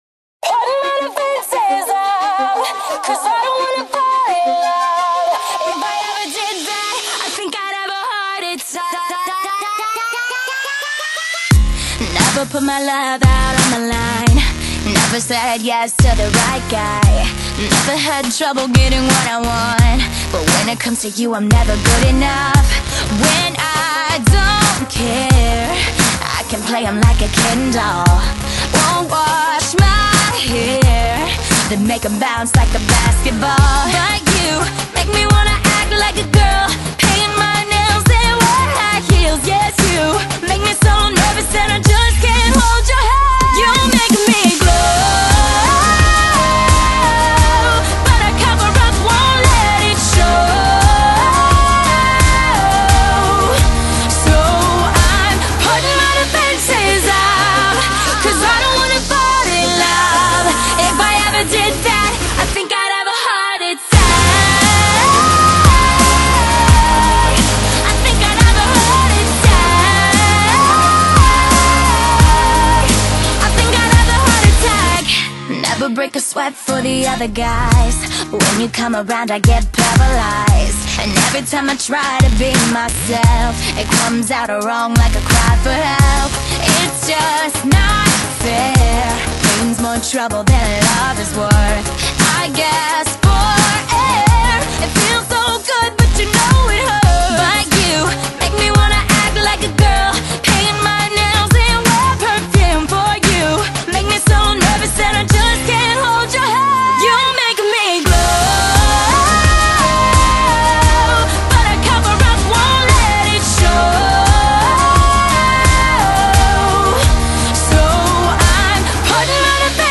音樂類型：西洋音樂
釋放超靚電子流行樂電流，展現兩個高八度音歌唱技巧
重擊的節奏，真誠的哀訴